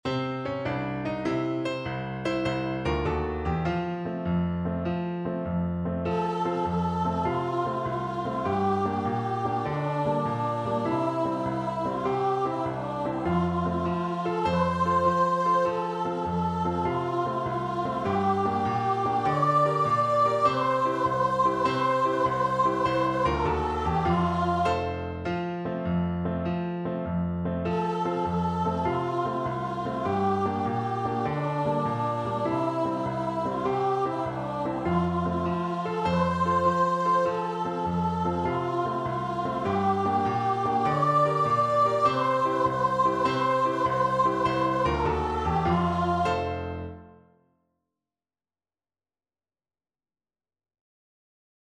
Free Sheet music for Voice
F major (Sounding Pitch) (View more F major Music for Voice )
Allegro .=c.100 (View more music marked Allegro)
6/8 (View more 6/8 Music)
C5-D6
Traditional (View more Traditional Voice Music)